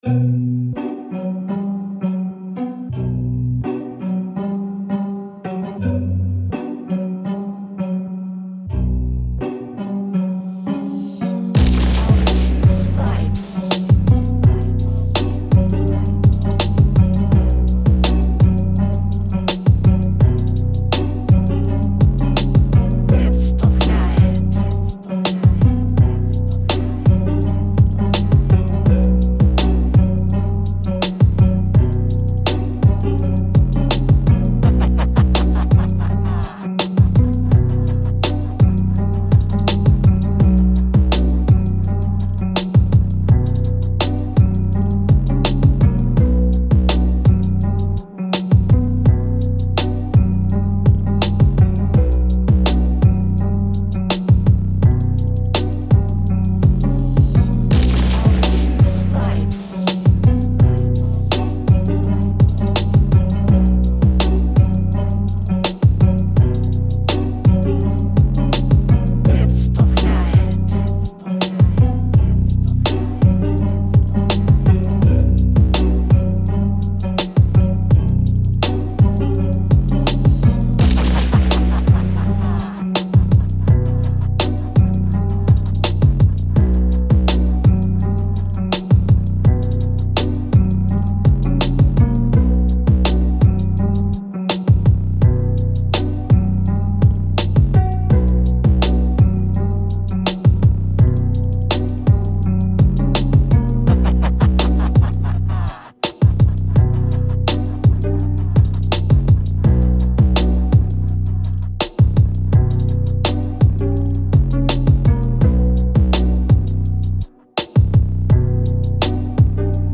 OG TRap Beat